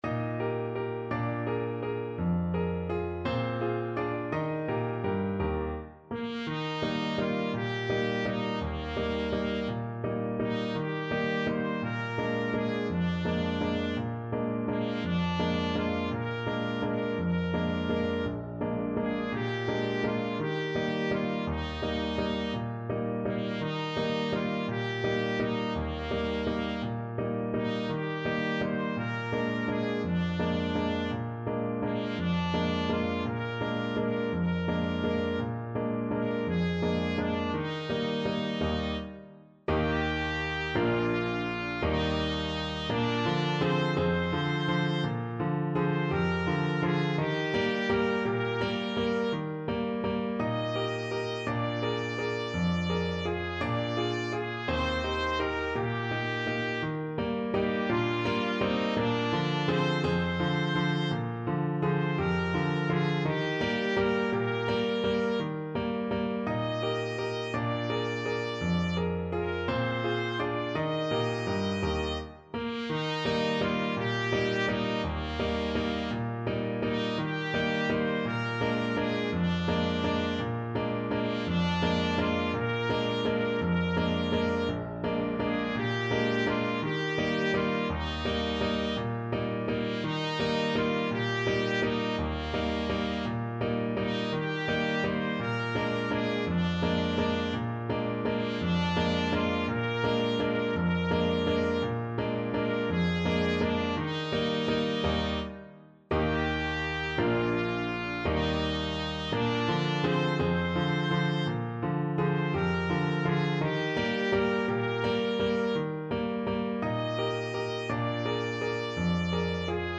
Trumpet version
3/4 (View more 3/4 Music)
One in a bar =c.168